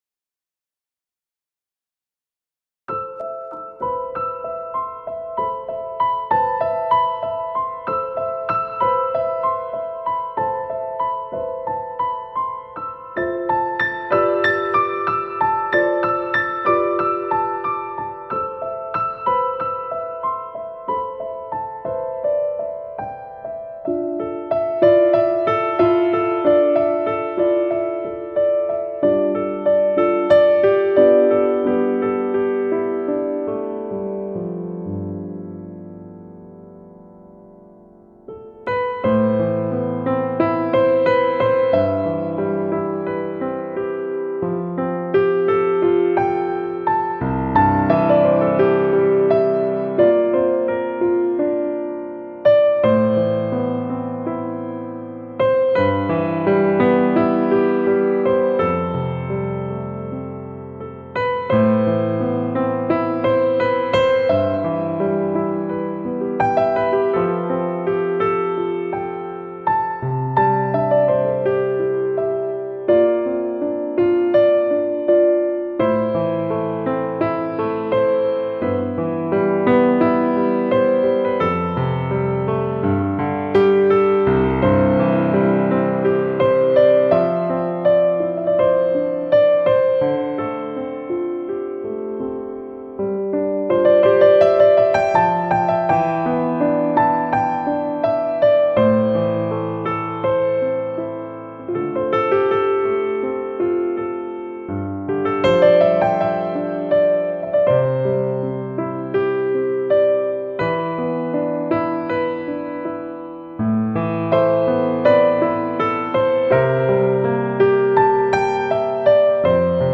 第三张钢琴演奏专辑
如诗的悠扬乐章，让人闻之仿佛置身吉野樱海的绝美古都，流连忘返。